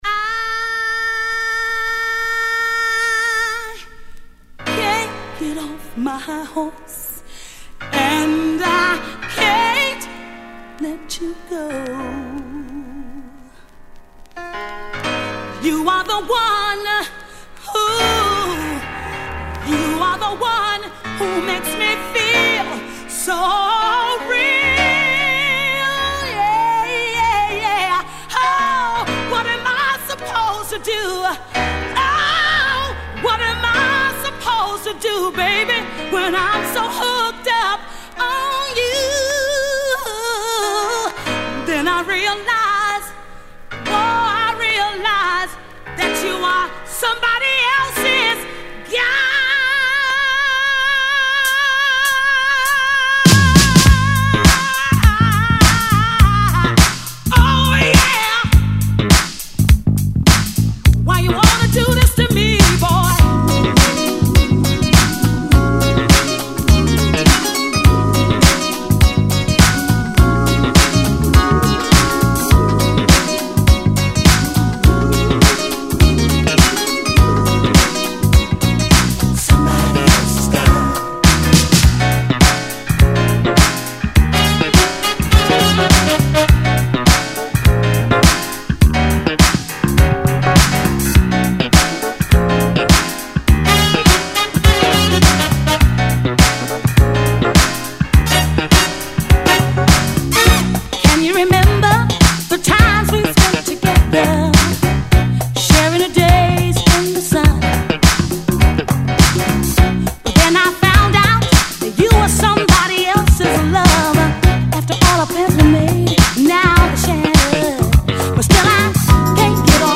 ホーム DISCO 80's 12' J
(Vocal)